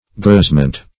gazement - definition of gazement - synonyms, pronunciation, spelling from Free Dictionary Search Result for " gazement" : The Collaborative International Dictionary of English v.0.48: Gazement \Gaze"ment\, n. View.
gazement.mp3